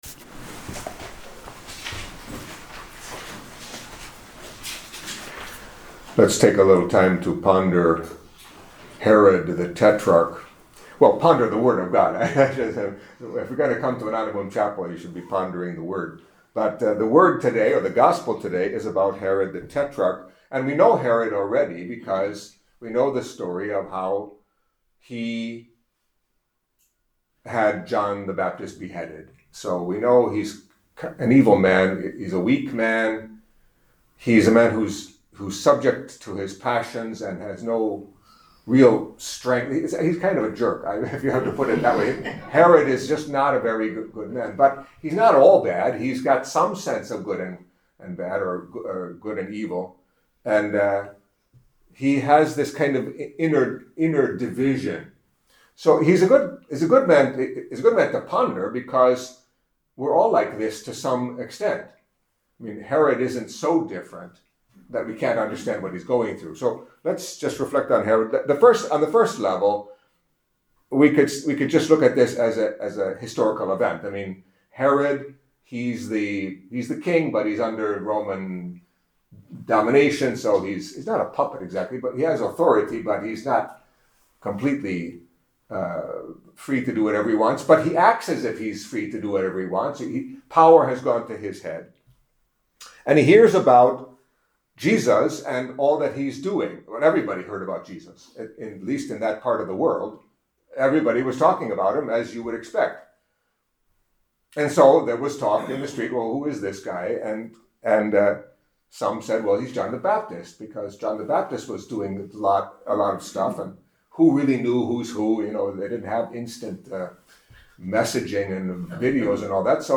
Catholic Mass homily for Thursday of the Twenty-Fifth Week in Ordinary Time